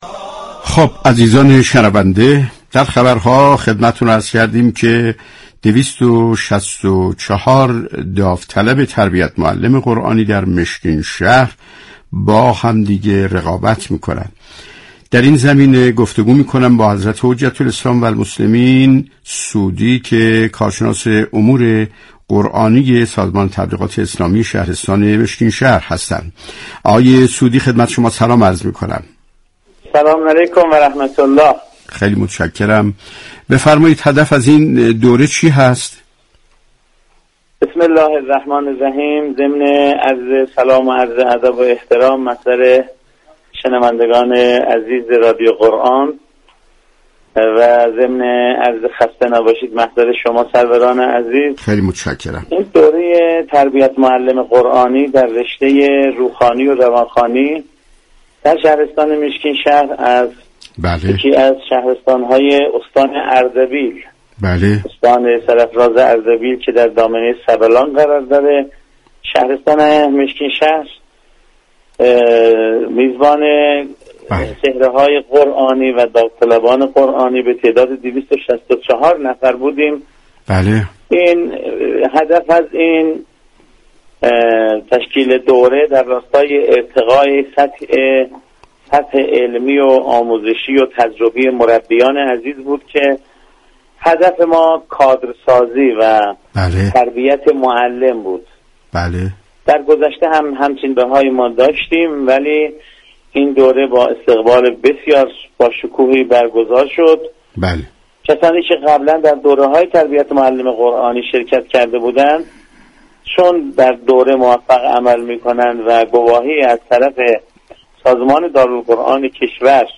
در والعصر رادیو قرآن عنوان شد؛ رقابت 264 داوطلب تربیت معلم قرآنی در مشگین شهر